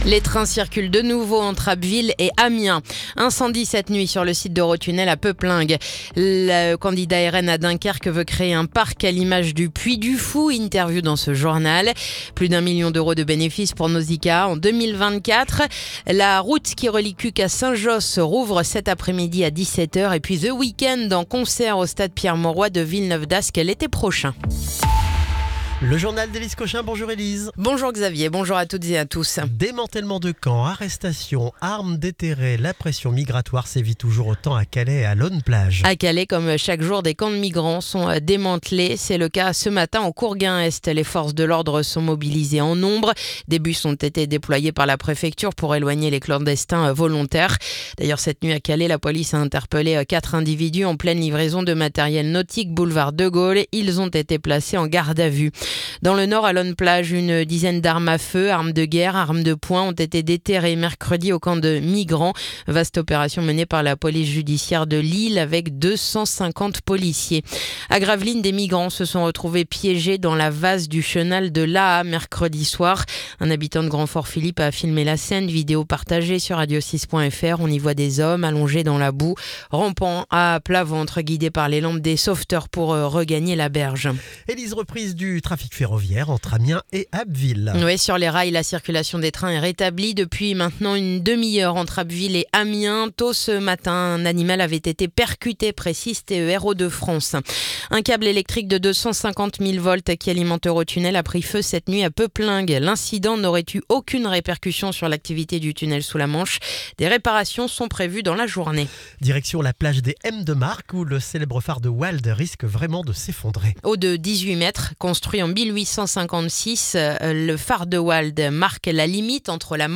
Le journal du vendredi 10 octobre